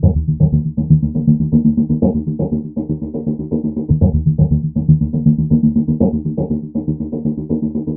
synth.mp3